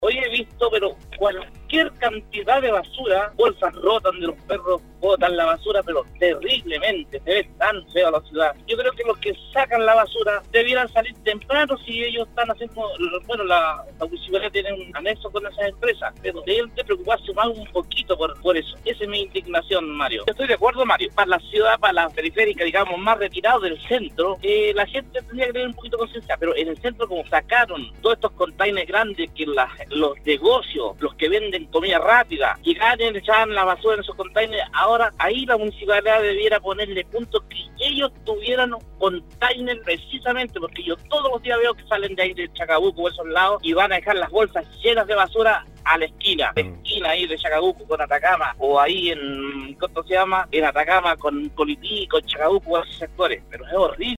La mañana de este lunes, auditores de Nostálgica se refirieron a las medidas que determinan las municipalidades en Atacama para el retiro de basura en las distintas comunas de la región.